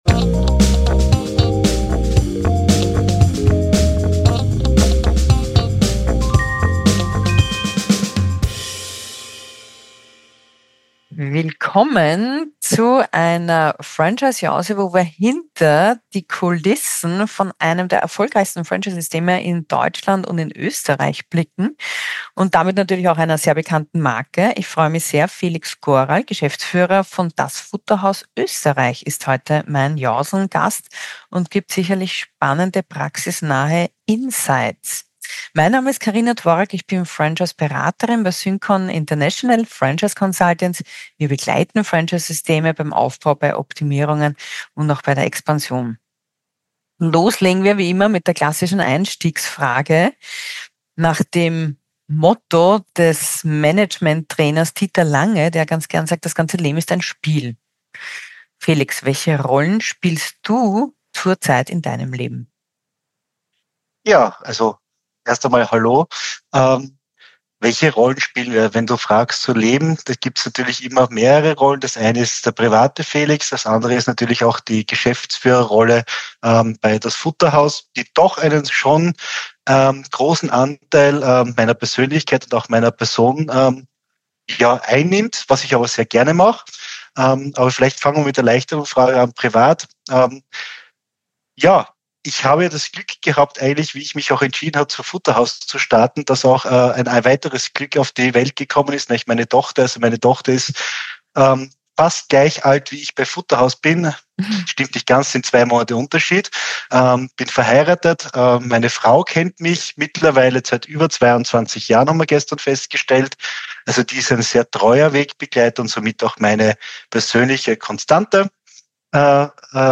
Ein Gespräch über Kundenverhalten, Ladenbau, White-Spots, Ritterschlag und Beiräte